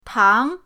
tang2.mp3